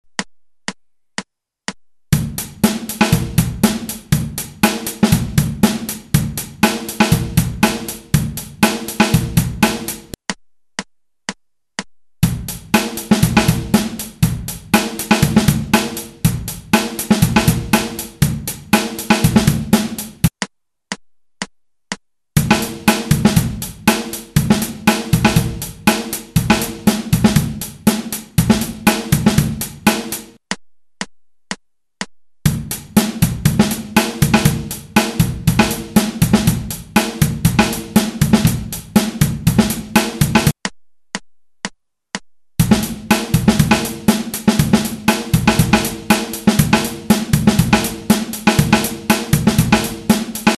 Синкопы Малого Барабана
sinqoupe_snare.mp3